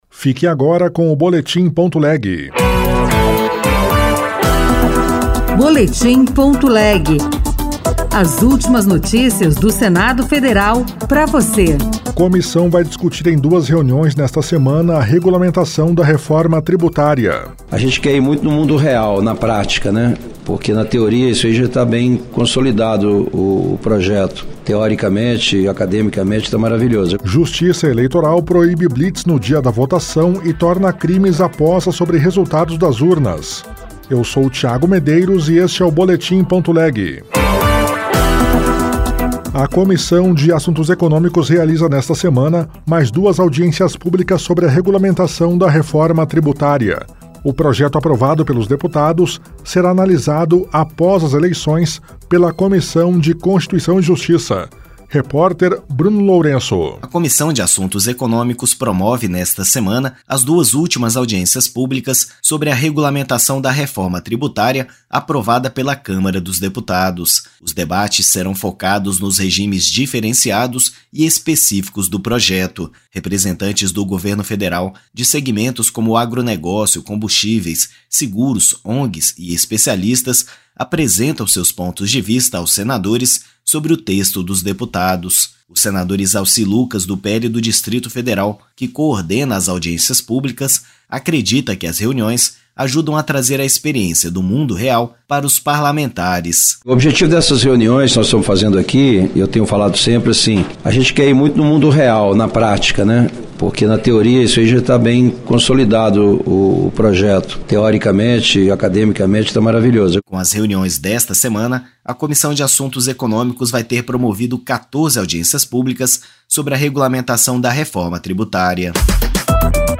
Boletim.leg